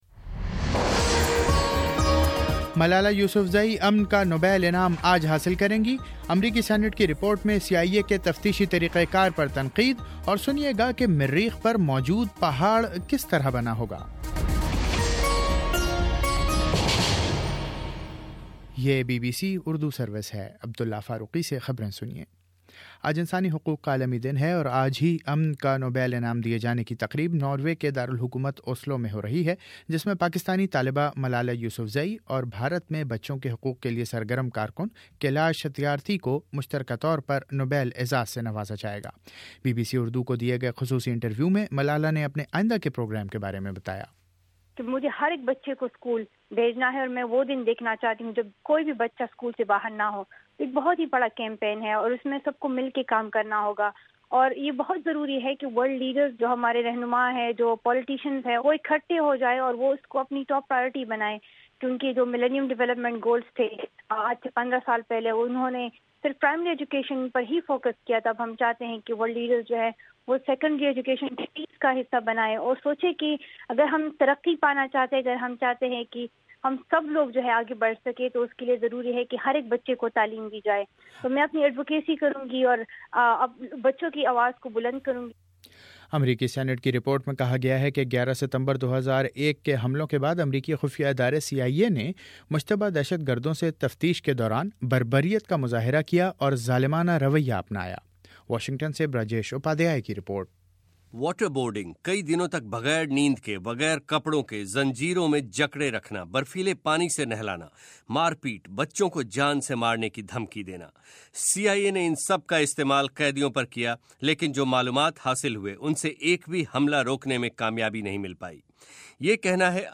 دسمبر10: صبح نو بجے کا نیوز بُلیٹن